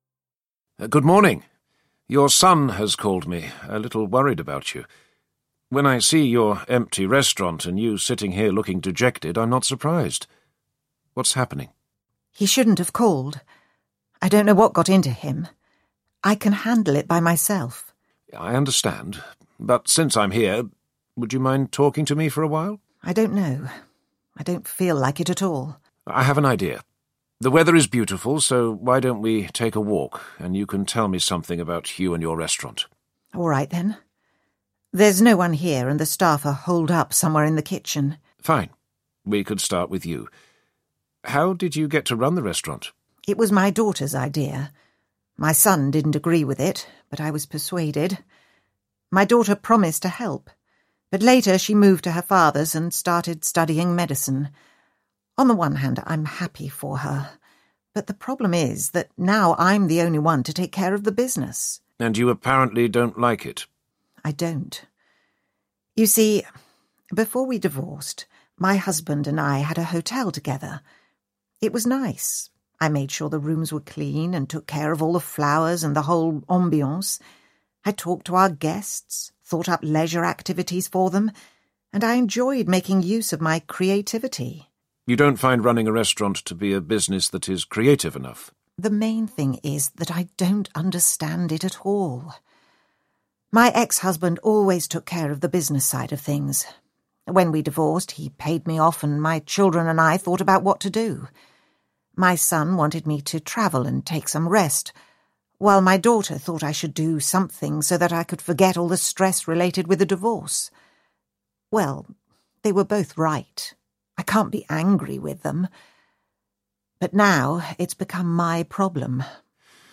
Audio knihaBusiness Risk Buster Intervenes 9
Ukázka z knihy